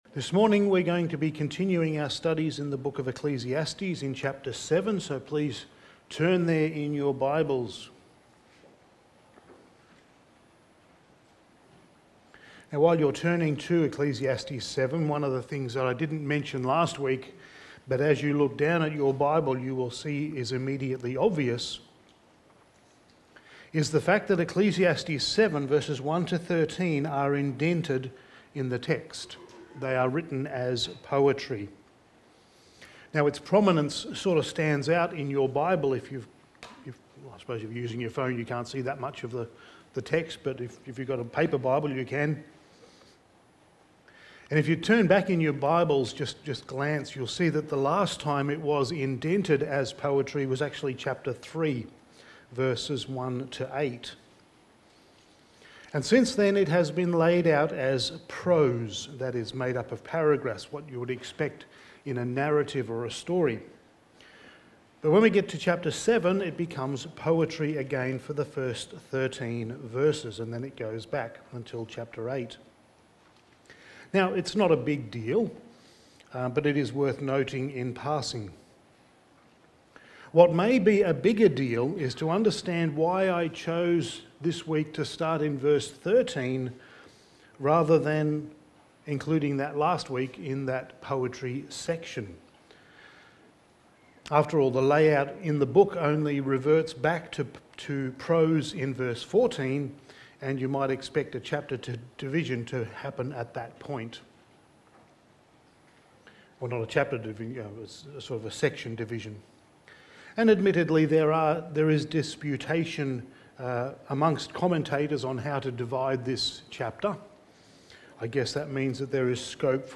Studies in the Book of Ecclesiastes Sermon 13: The Crook in the Lot
Service Type: Sunday Morning